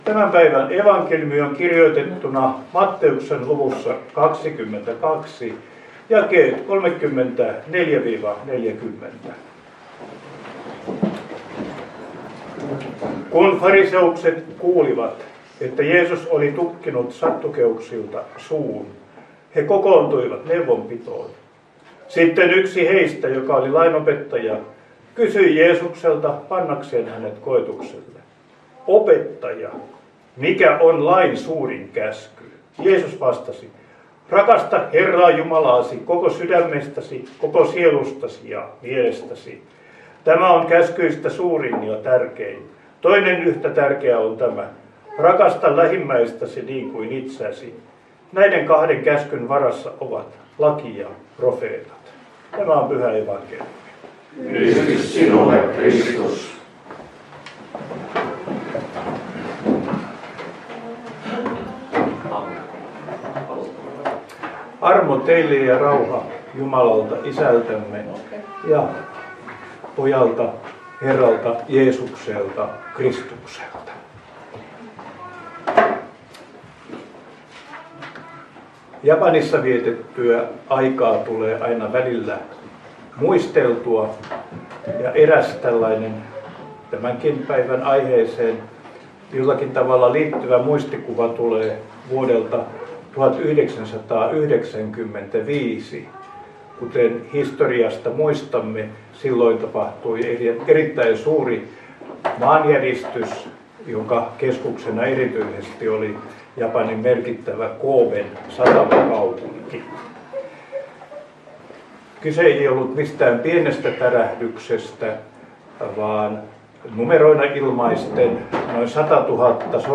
Lappeenranta